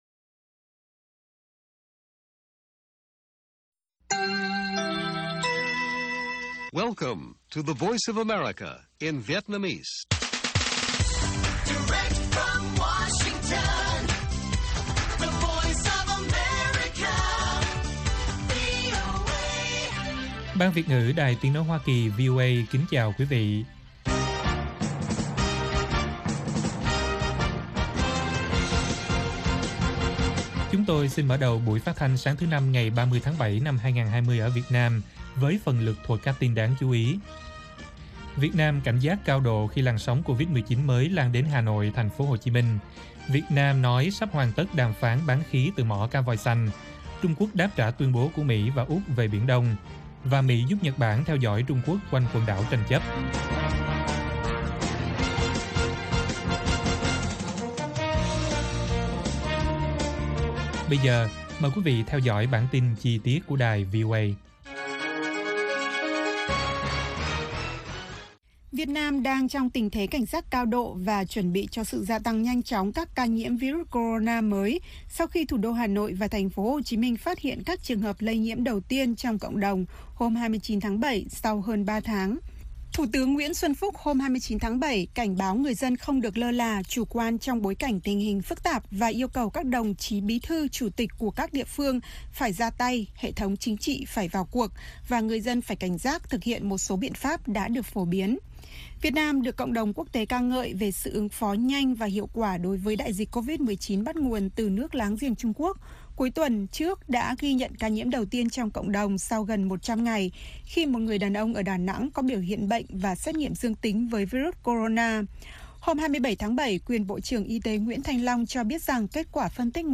Bản tin VOA ngày 30/7/2020